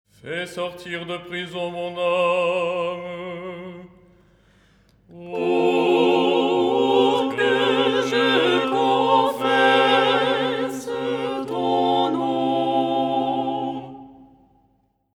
Ensemble
ton4-03-mixte.mp3